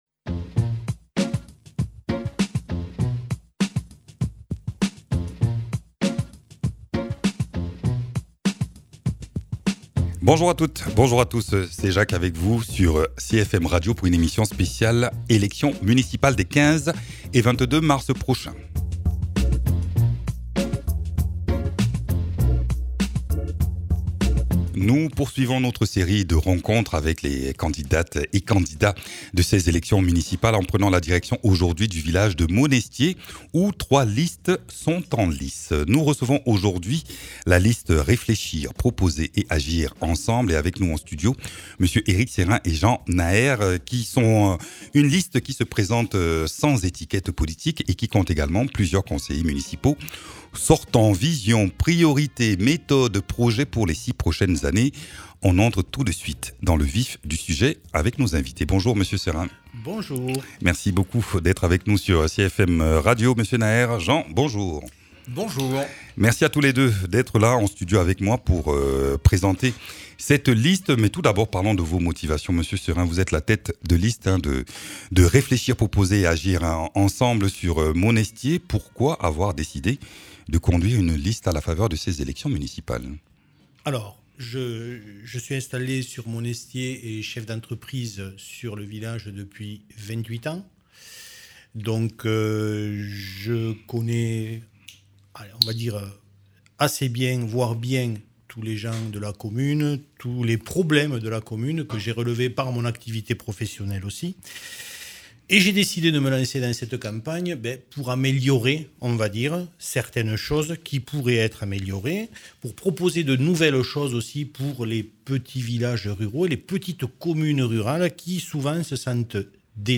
candidats aux municipales à Monestiés.